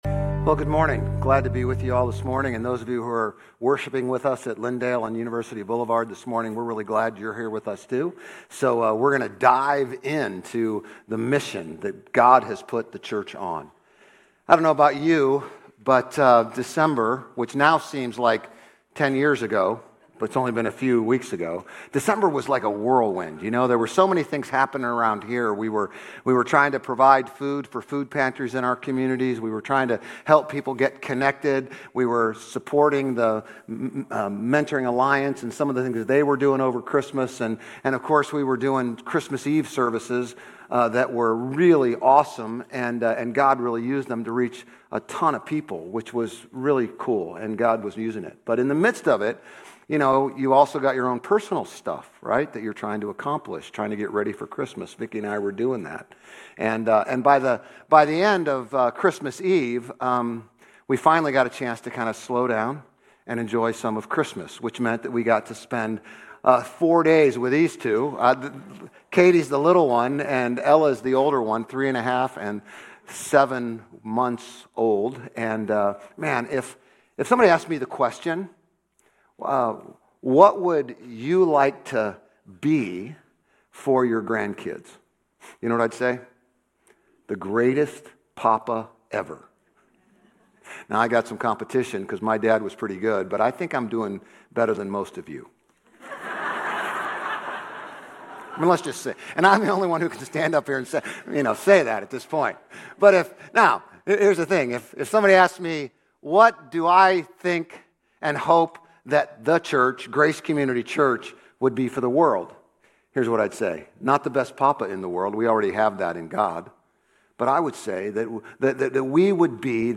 Grace Community Church University Blvd Campus Sermons 1_18 University Blvd Campus Jan 19 2026 | 00:30:53 1x 00:00 / 00:30:53 Subscribe Share RSS Feed Share Link Embed